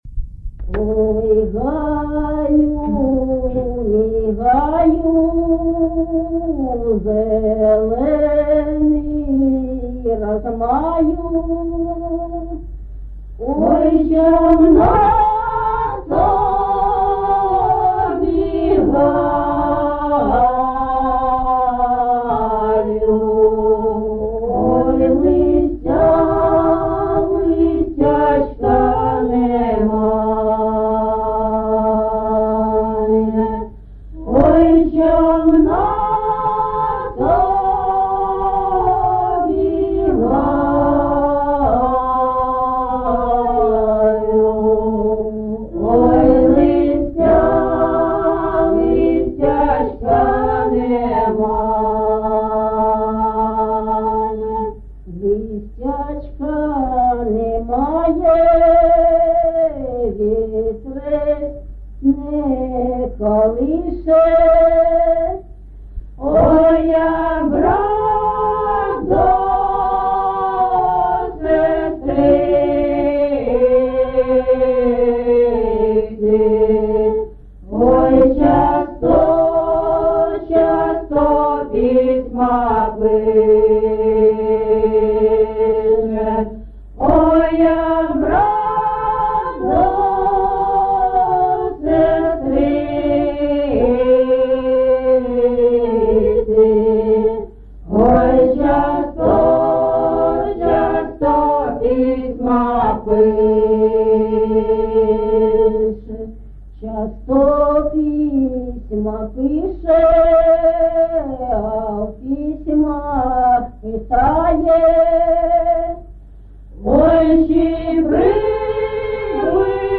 ЖанрПісні з особистого та родинного життя, Балади
Місце записум. Єнакієве, Горлівський район, Донецька обл., Україна, Слобожанщина